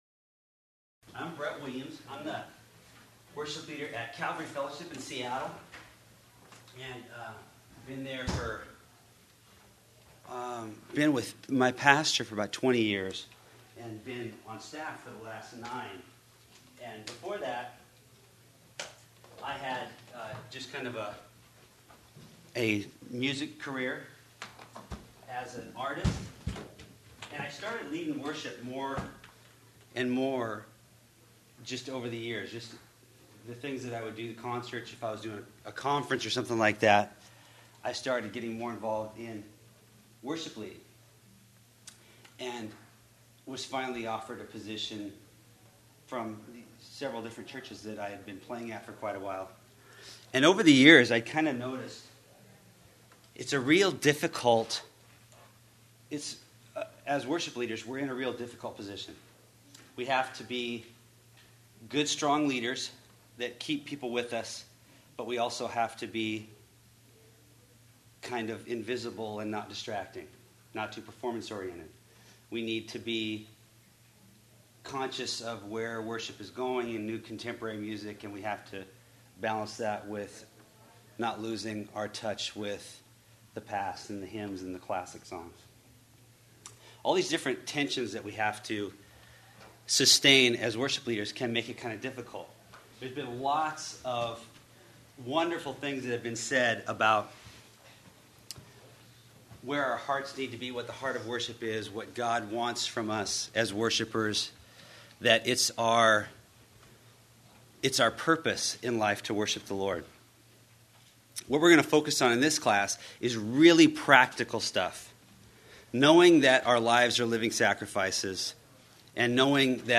Workshop: Essentials Of Worship Leading On Guitar
Series: 2005 Calvary Chapel Worship Leader Conference
Campus: Calvary Chapel Costa Mesa